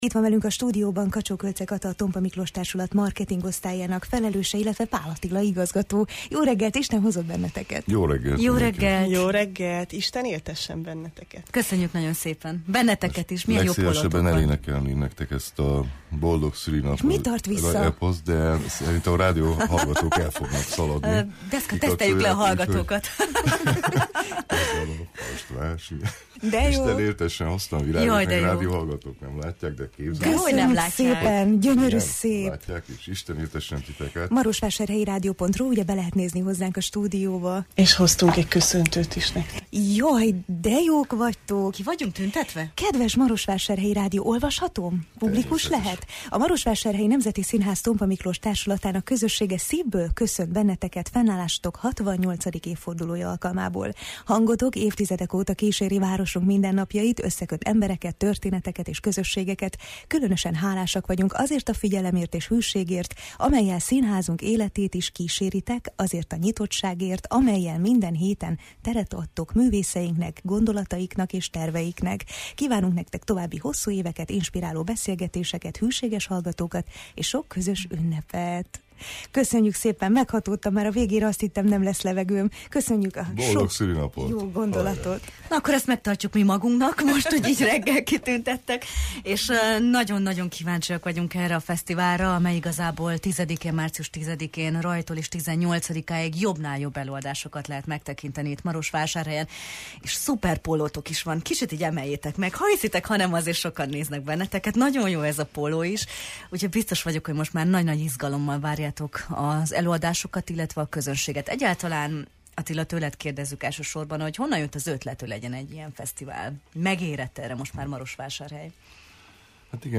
A fesztiválról a Jó reggelt, Erdély! című műsorban beszélt